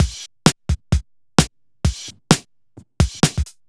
Breaks Or House 03.wav